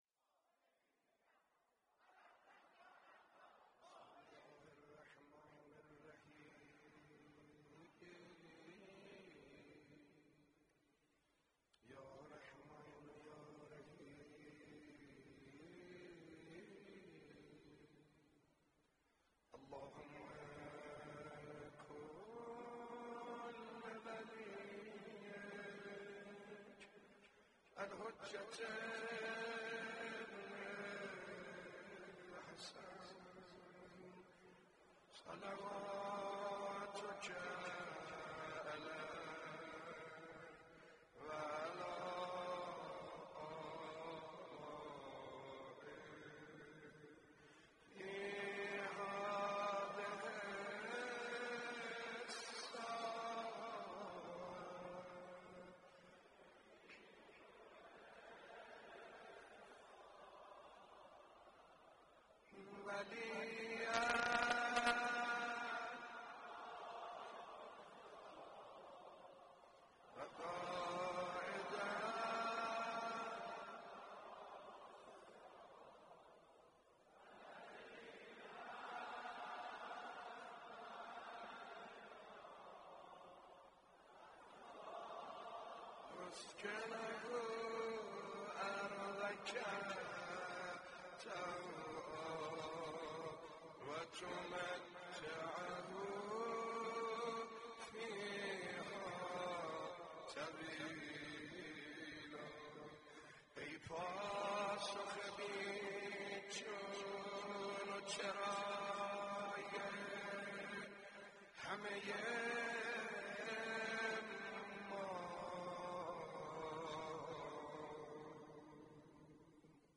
رسانه ها سخنرانی حجت الاسلام پناهیان مداحی حاج سعید حدادیان منتخب مداحی با کیفیت مناسب مداحی کامل R30441/P30441